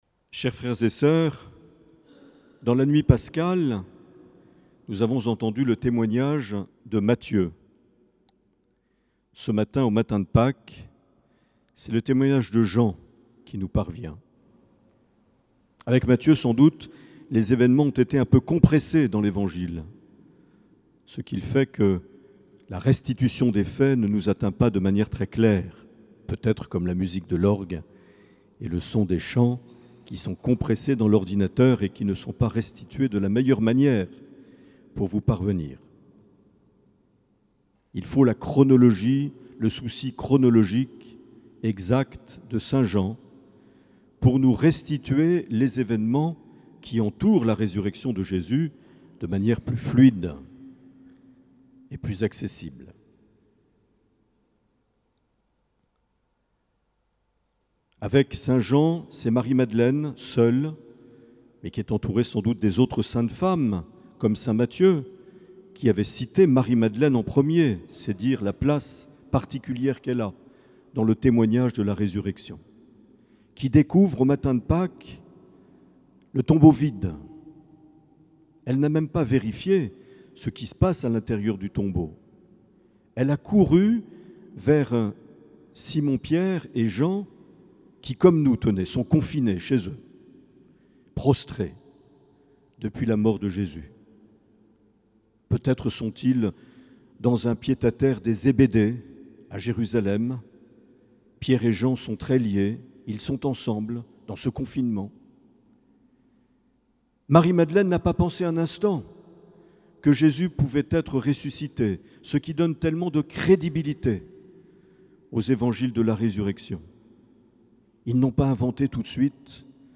12 avril 2020 - Jour de Pâques - Cathédrale de Bayonne
Une émission présentée par Monseigneur Marc Aillet